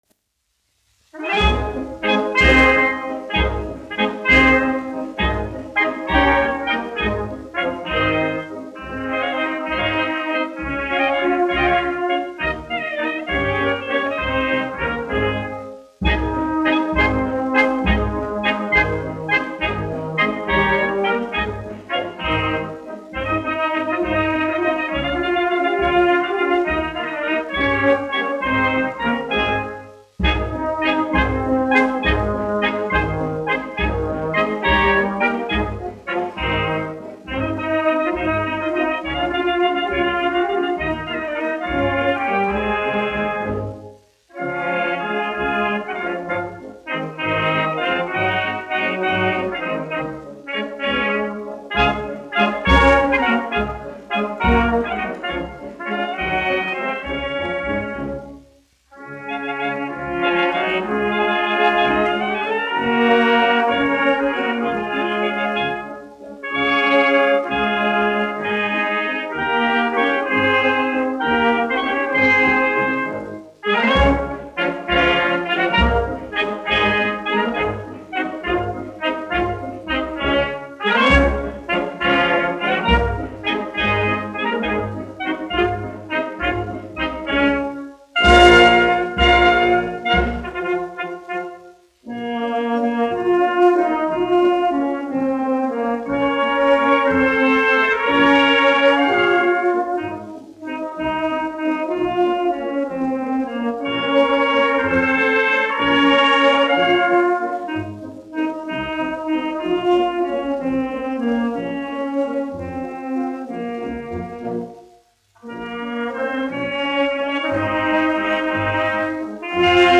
1 skpl. : analogs, 78 apgr/min, mono ; 25 cm
Valši
Pūtēju orķestra mūzika
Tautasdziesmas, latviešu--Instrumentāli pārlikumi
Skaņuplate